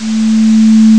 This is a waveform with an additional noise generated by a continuous uniform distribution with support [-50, 100].
noise1.wav